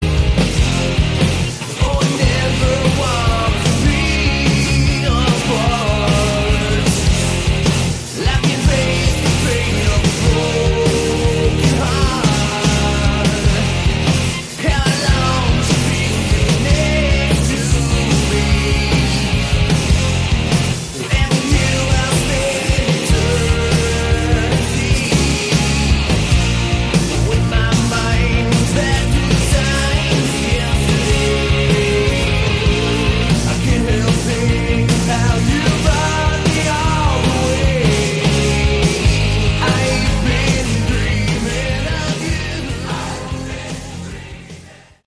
Category: Hard Rock
Vocals, Guitars, Keyboards, Piano